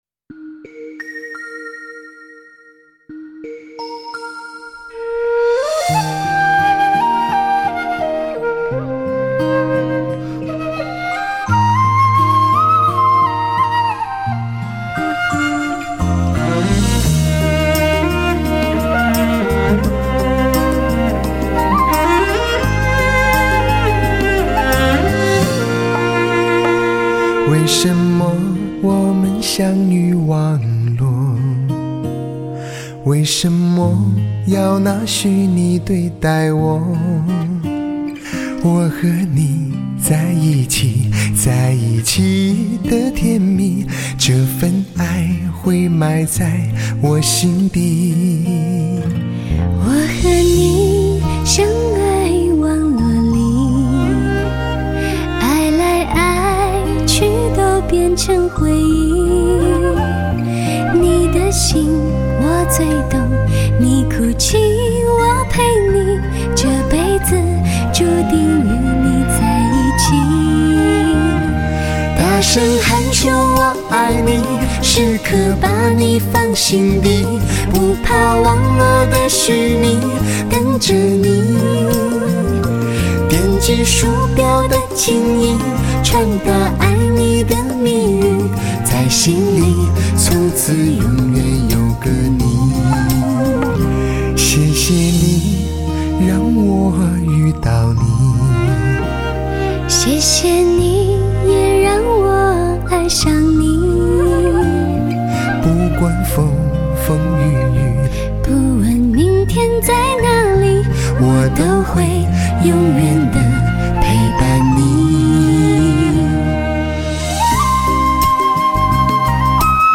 最真实的好声音 抒情男声新风格
独特的声音 中频感染力最具迷人 温柔为你而歌
精确的音乐表达力和动态震撼效果出色 温情满溢